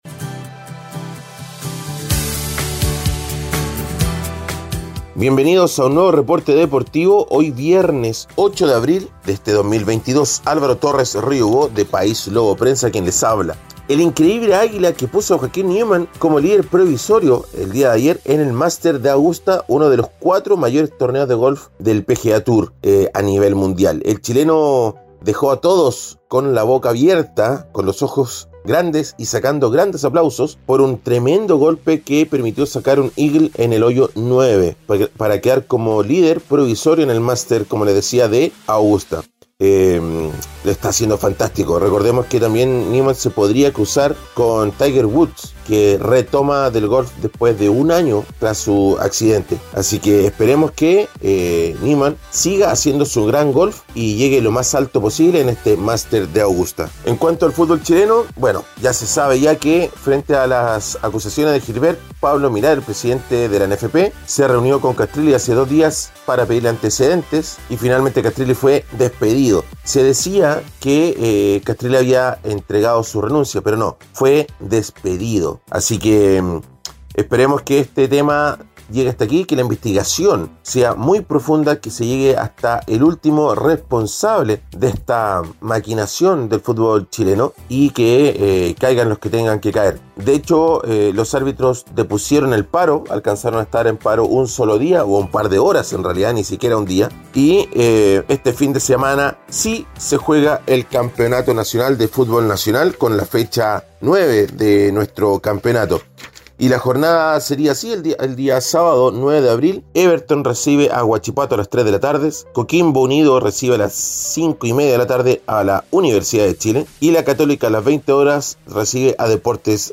Reporte Deportivo ▶ Podcast 08 de abril de 2022